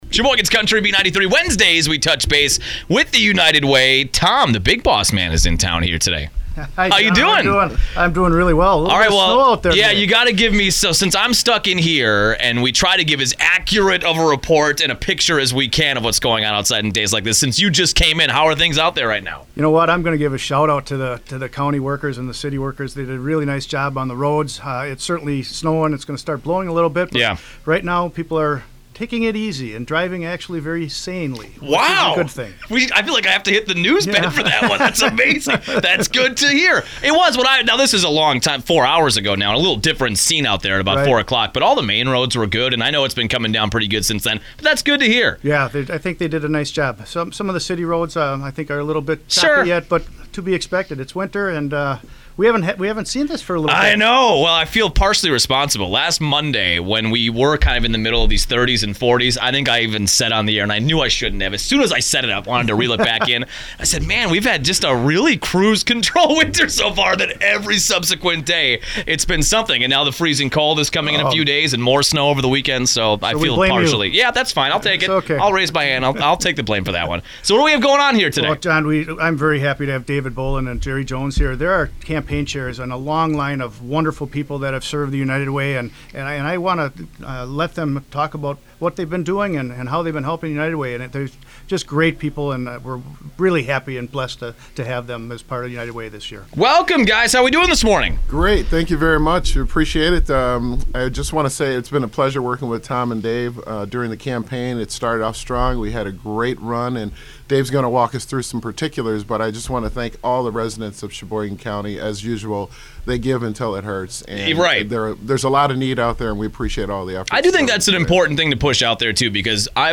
United Way Update - Radio Spot 1.23.19